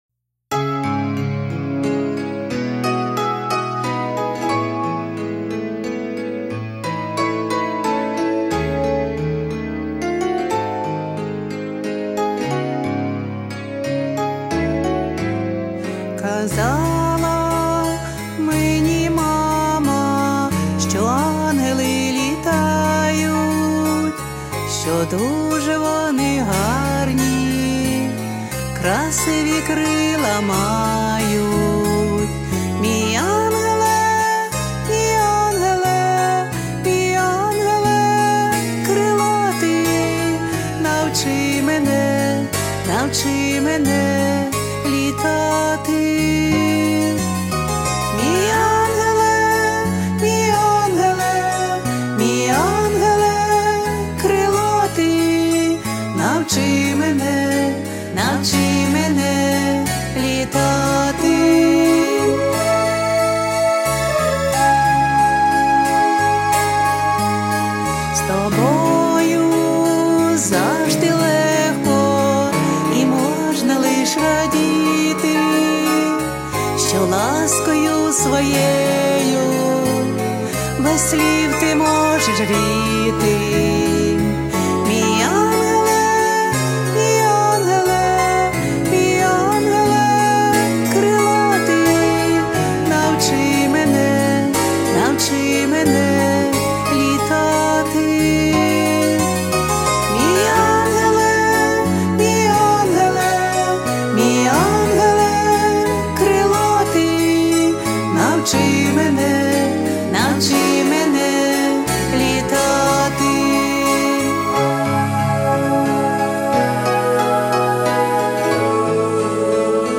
Рубрика: Поезія, Авторська пісня
Гарна, легка, світла пісенька! 16 cup 16